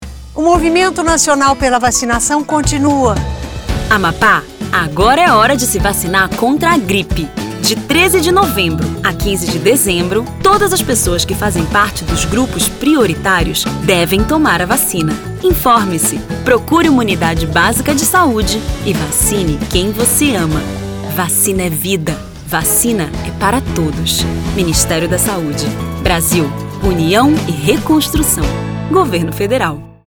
Amapá: Spot - Vacinação Contra a Gripe no Amapá - 30seg .mp3